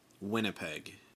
Winnipeg (/ˈwɪnɪpɛɡ/